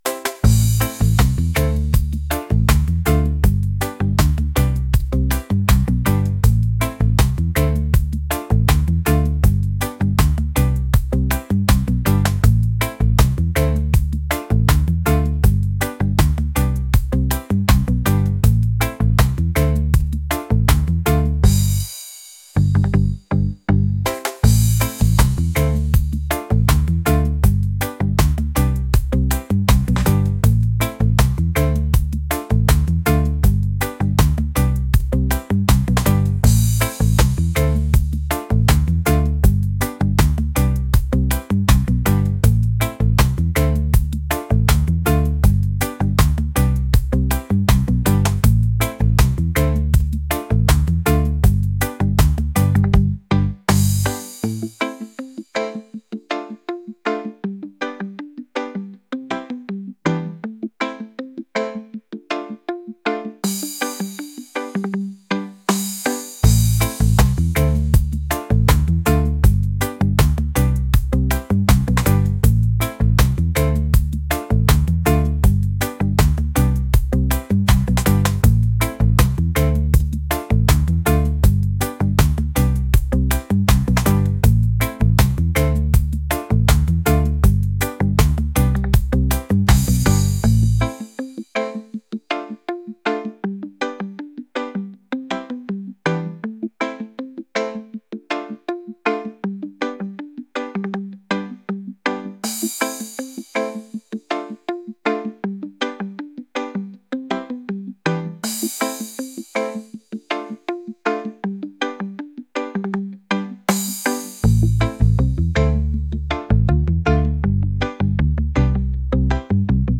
reggae | folk | lounge